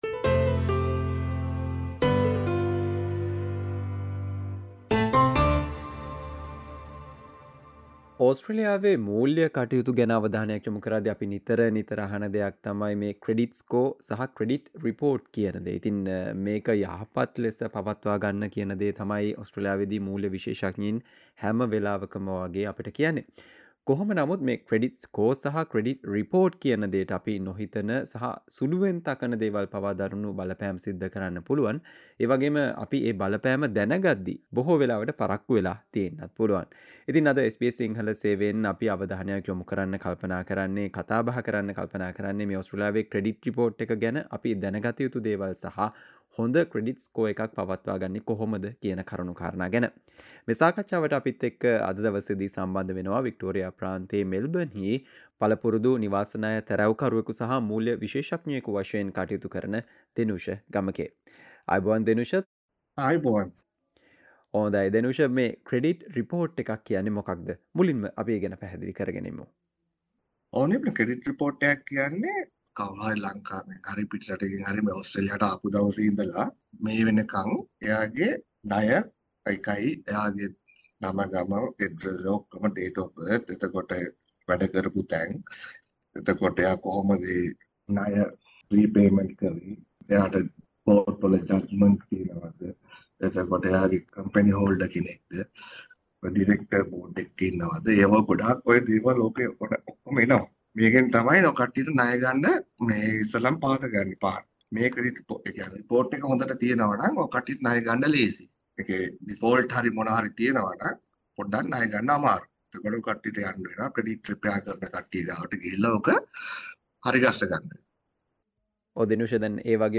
SBS Sinhala discussion on what we need to know about credit report in Australia and maintaining a good credit score